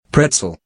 A pretzel (/ˈprɛtsəl/
PRET-səl; from German: Breze(l) or Bretzel, pronounced [ˈbʁeːtsl̩]